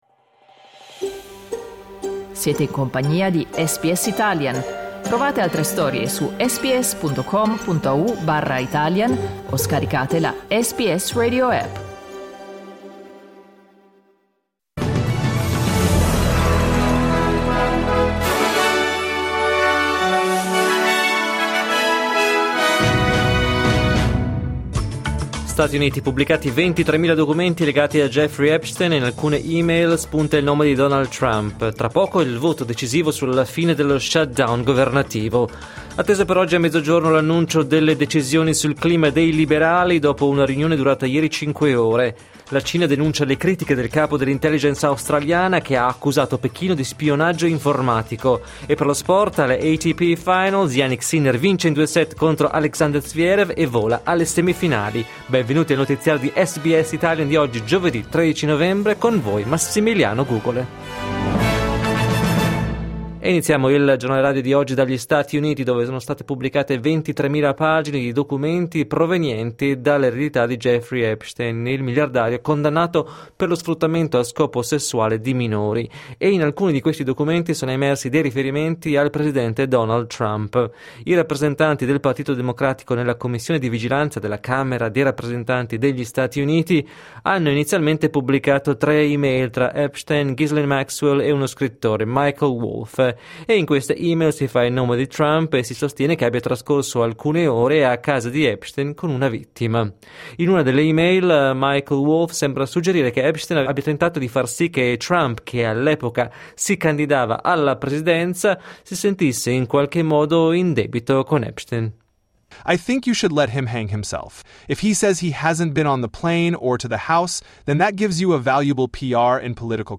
Giornale radio giovedì 13 novembre 2025
Il notiziario di SBS in italiano.